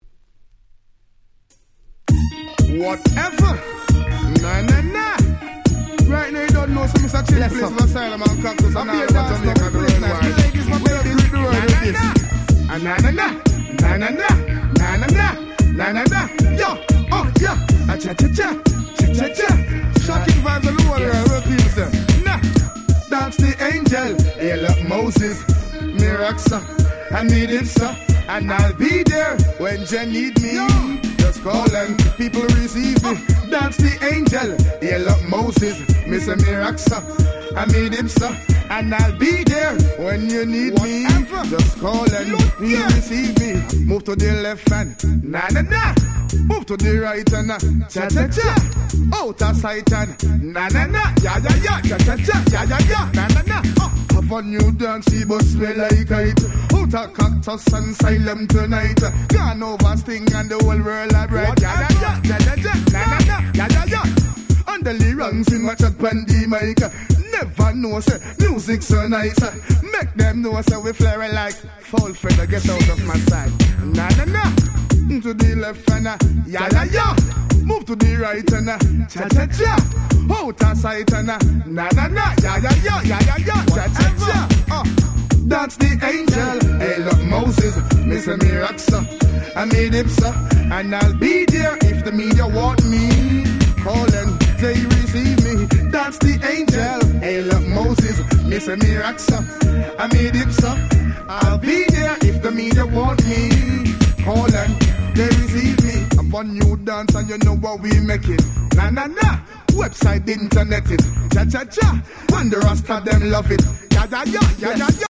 00' Smash Hit Dancehall !!
「ナナナ、チャチャチャ！」とキャッチーなサビが非常に印象的です。
ダンスホールレゲエ プロモオンリー